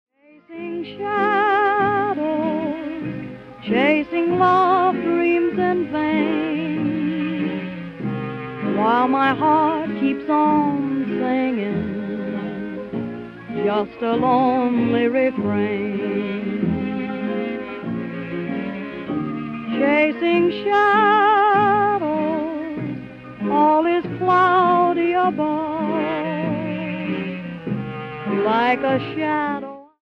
Original recordings from 1931 - 1941, they're all winners.